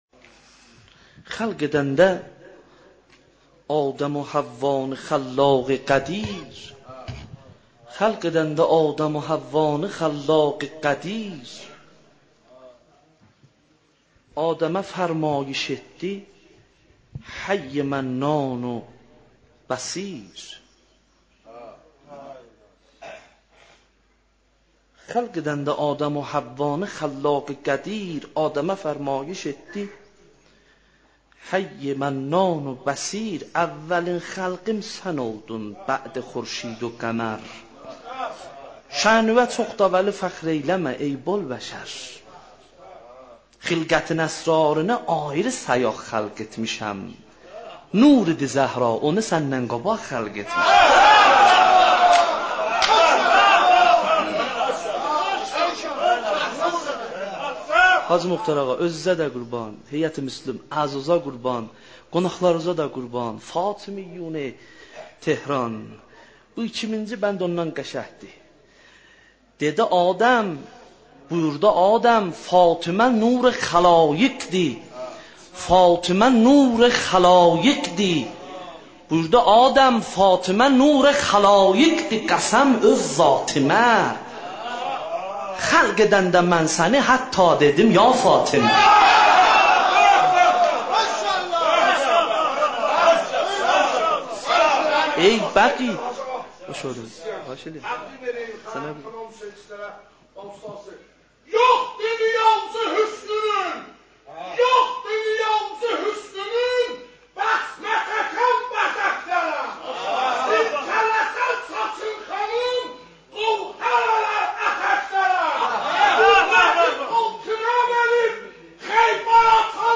دانلود مداحی با من بمان زهرا - دانلود ریمیکس و آهنگ جدید
ذکر مصیبت بانوی مظلومه عالم حضرت زهرا(س